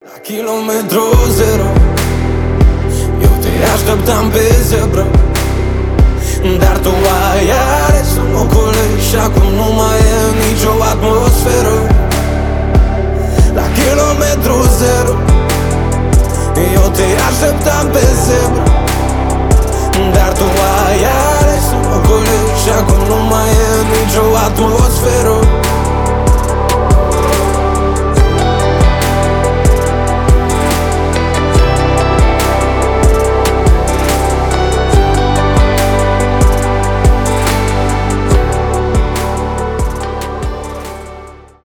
румынские , поп , красивые , рэп